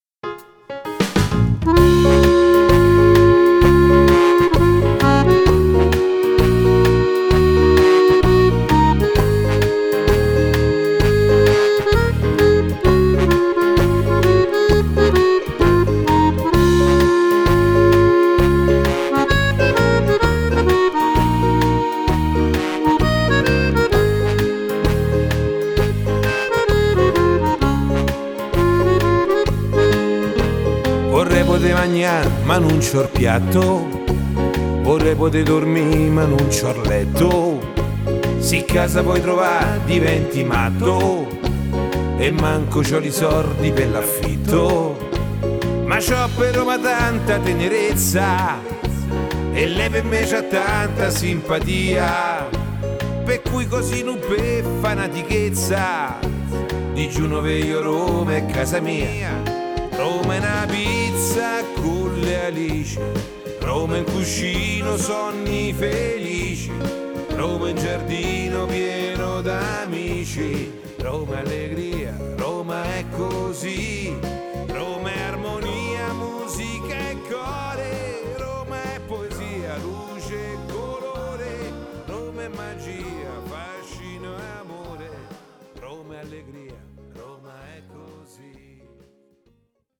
hully gully
12 brani ballabili, 10 inediti e due cover: